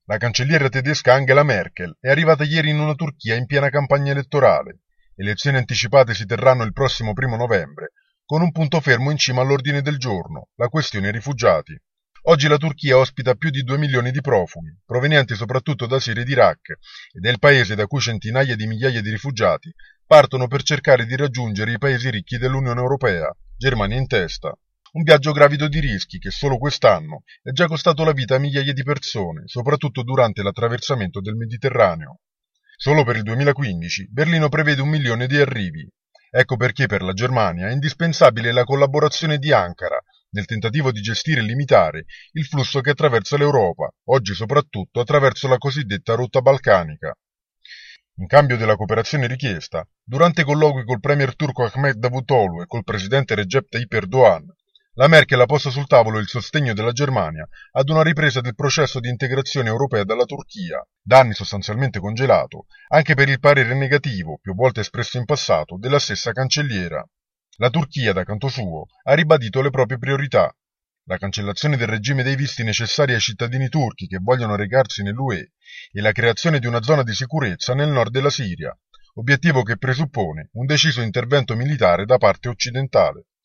Questione rifugiati al centro della visita di ieri in Turchia di Angela Merkel. Berlino chiede collaborazione ad Ankara e promette di far ripartire il processo di integrazione europea. Il servizio
per il GR di Radio Capodistria